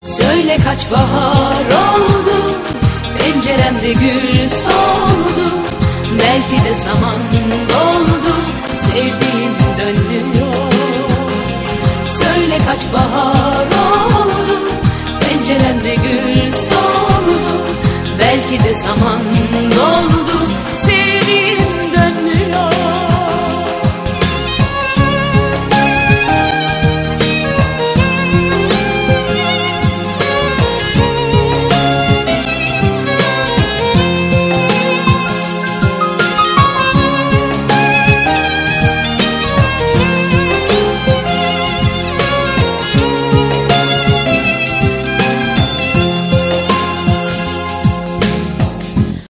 спокойные , поп
восточные